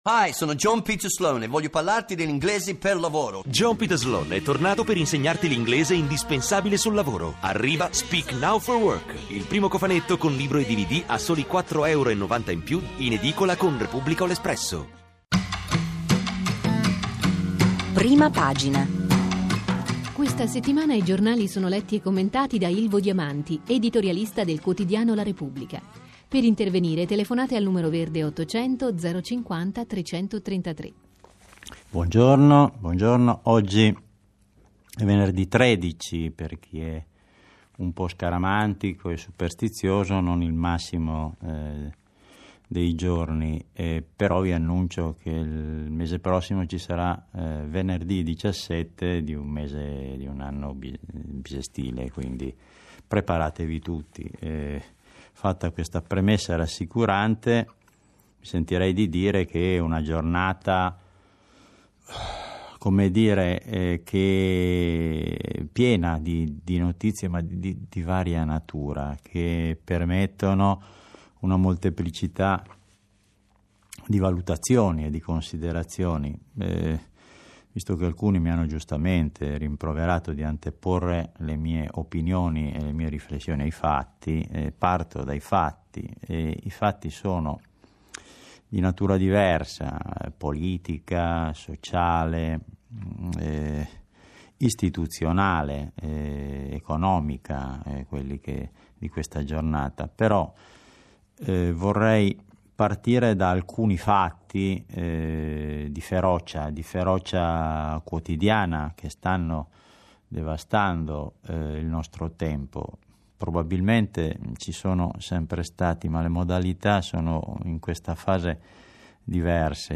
Ilvo Diamanti legge i quotidiani, 13 gennaio 2012